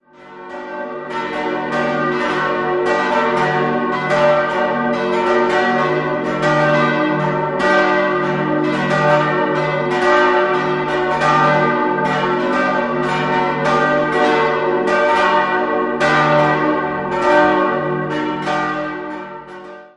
Jahrhundert stammen die Altäre im Inneren. 4-stimmiges Salve-Regina-Geläute: d'-fis'-a'-h' Alle Glocken wurden 1950 von Karl Hamm in Regensburg gegossen.